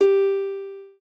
lyre_g.ogg